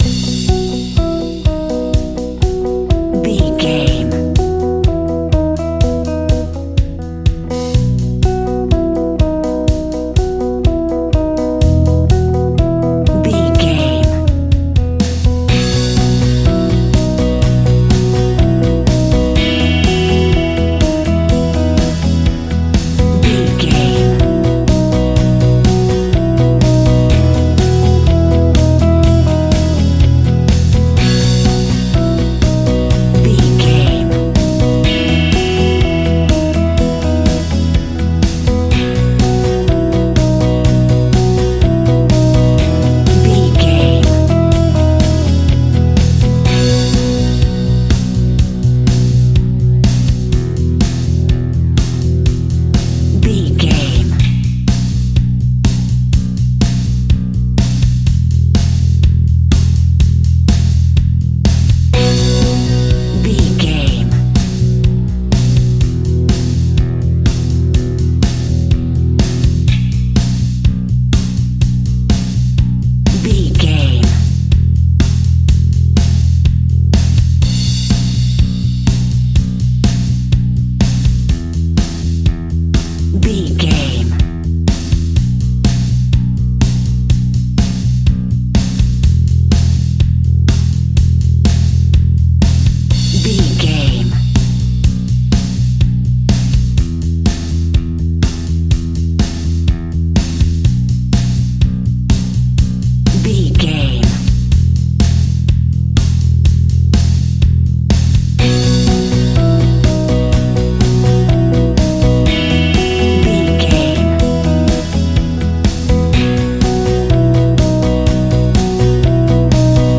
Ionian/Major
indie pop
fun
energetic
uplifting
instrumentals
guitars
bass
drums
piano
organ